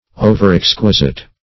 Search Result for " overexquisite" : The Collaborative International Dictionary of English v.0.48: Overexquisite \O"ver*ex"qui*site\, a. Too exquisite; too exact or nice; too careful.